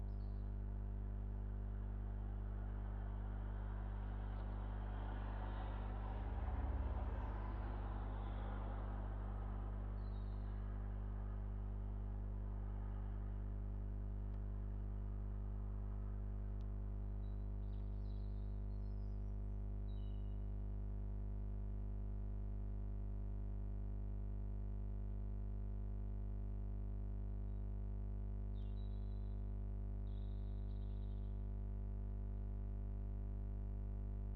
Test - contact mic, window, through Mbox into Logic